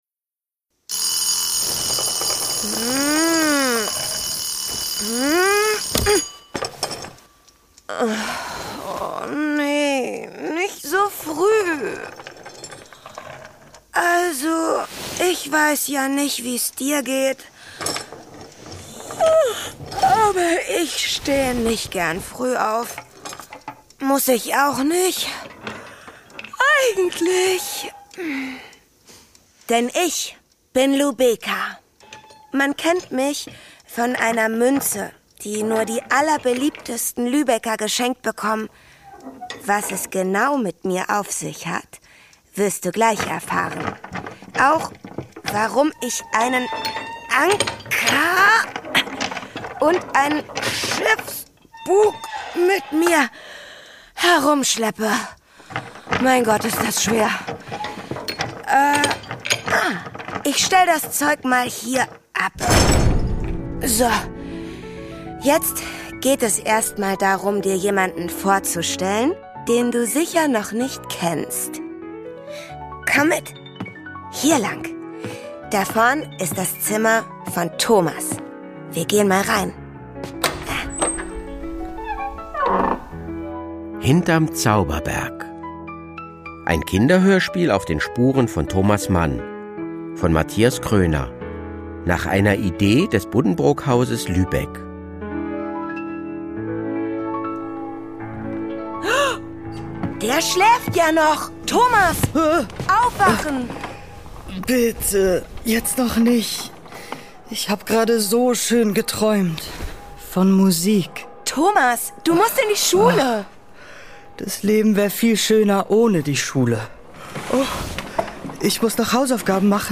Vom Schüler mit Schwierigkeiten im Katharineum über das Kennenlernen mit seiner Frau in München, bis hin zu seiner Flucht ins Exil – Lubeca begleitet Thomas Mann durch bewegte Zeiten, voller Fragen, Mut und literarischem Glanz. Mit kindgerechtem Charme, klangvoller Inszenierung und humorvollen Zwischentönen bringt das Hörspiel Thomas Manns Leben und Werke zum Klingen: „Die Buddenbrooks“, „Der Zauberberg“, „Doktor Faustus“ – all diese Bücher und ihre Geschichten begegnen den Hörer*innen dort, wo sie entstanden.
Mit Musik, Magie und vielen Momenten zum Staunen!